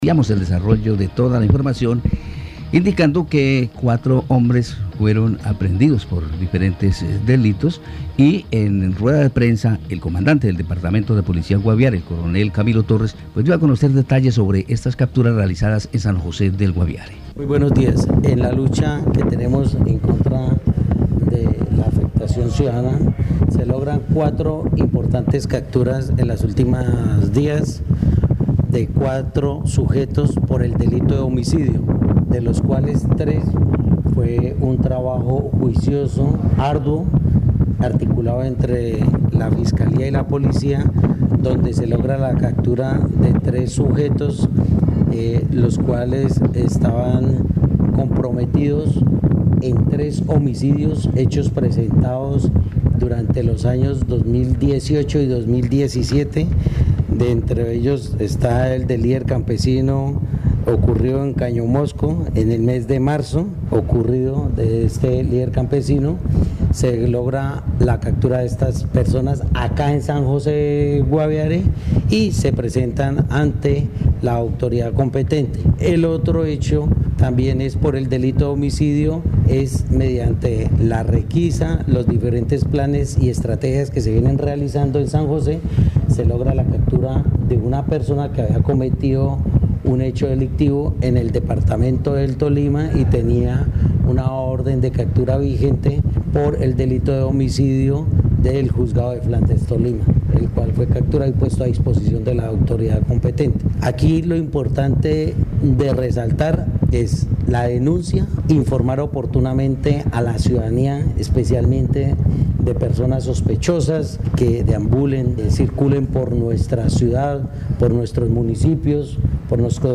Escuche al Coronel Camilo Torres, comandante de Policía Guaviare.
En rueda de prensa el Coronel Camilo Torres, comandante del Departamento de Policía Guaviare confirmó la captura de cuatro hombres de 48,42, 49 y 25 años de edad por los delitos de homicidio y fabricación, tráfico porte o tenencia de armas de fuego.